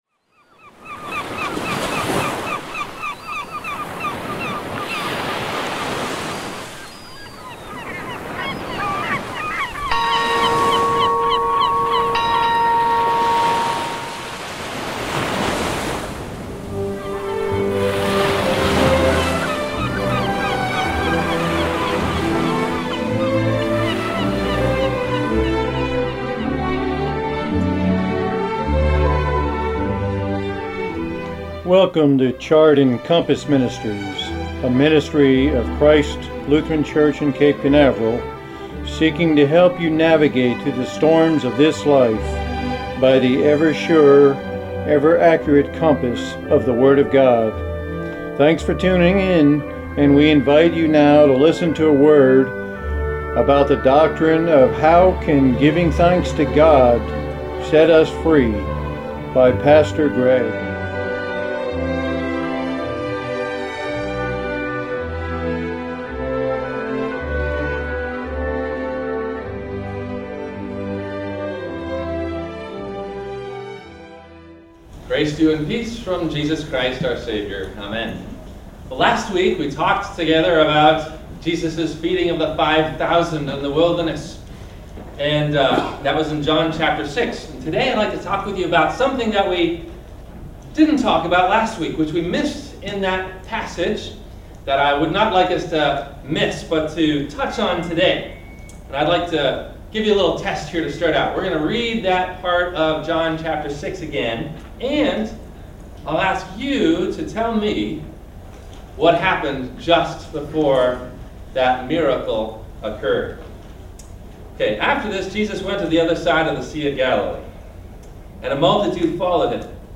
How Can Thanksgiving Set Me Free ? – Sermon – February 09 2014
We will begin our Radio Show Ministry in March, and the link below, is a test of our first intro and sermon message for :